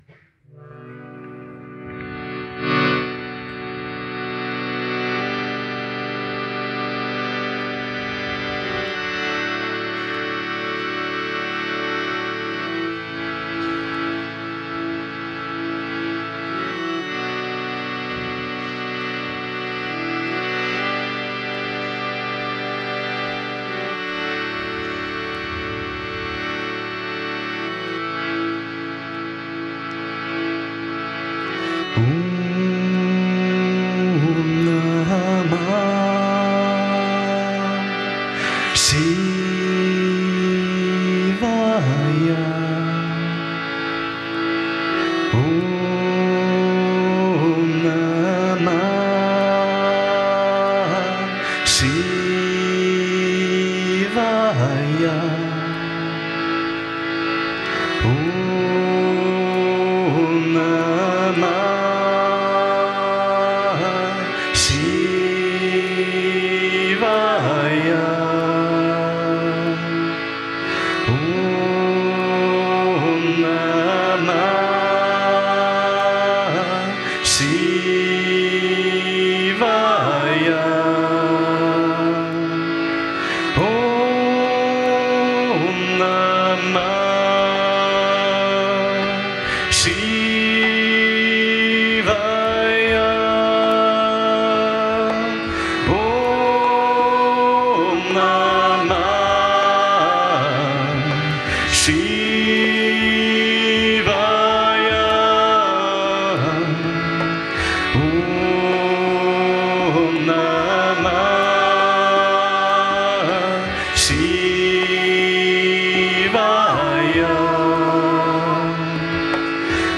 mantra